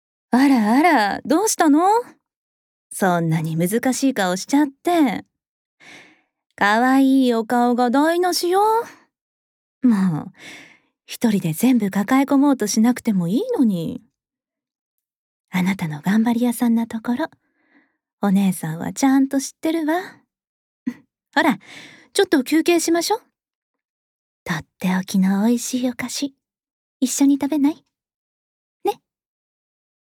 ボイスサンプル
優しいお姉さん